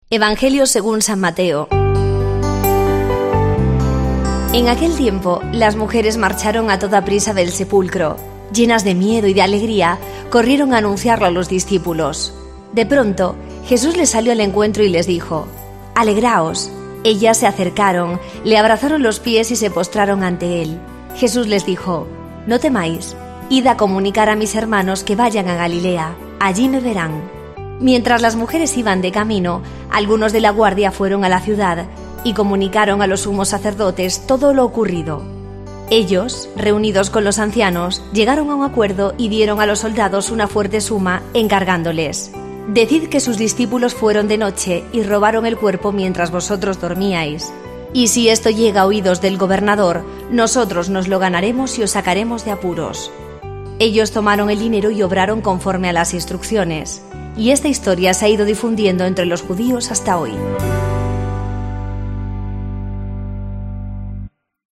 Lectura del santo Evangelio según san Mateo 28,8-15